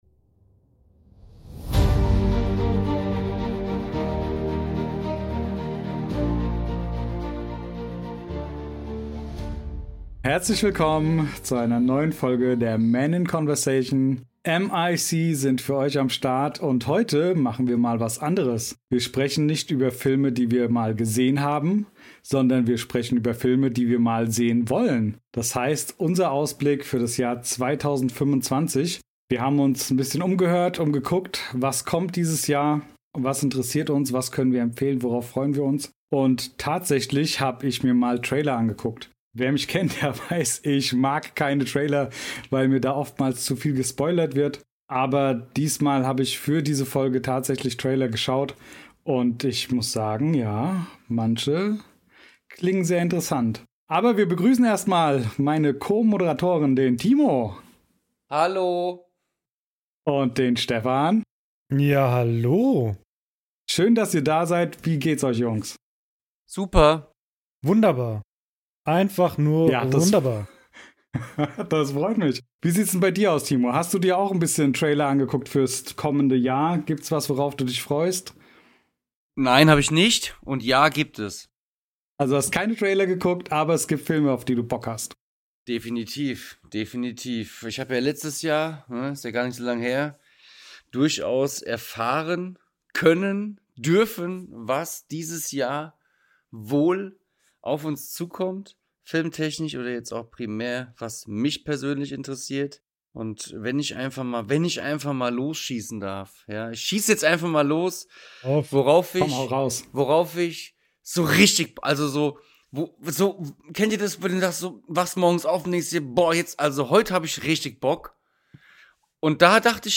Wir sind drei ganz normale Typen, aber wir haben Spaß bei der Sache und jeder bringt seine ganz eigene Meinung mit rein.